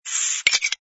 sfx_open_beer03.wav